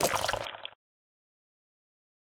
PixelPerfectionCE/assets/minecraft/sounds/mob/guardian/flop1.ogg at mc116
flop1.ogg